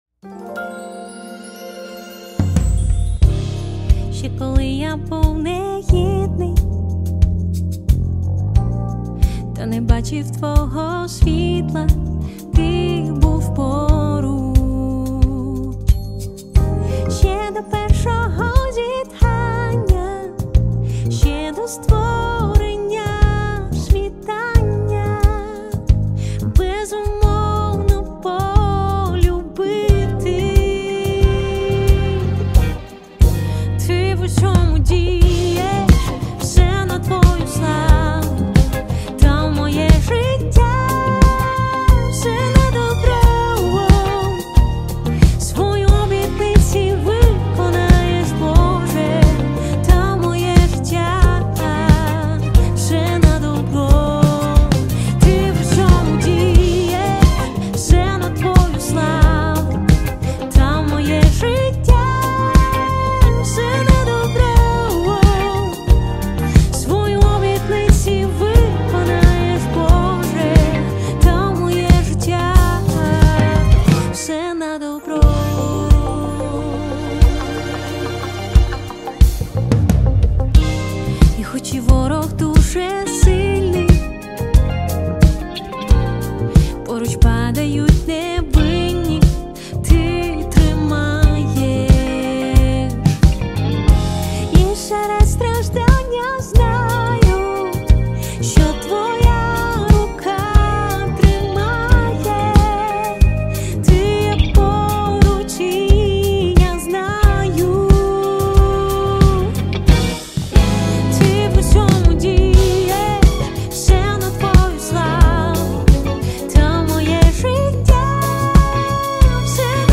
142 просмотра 57 прослушиваний 15 скачиваний BPM: 90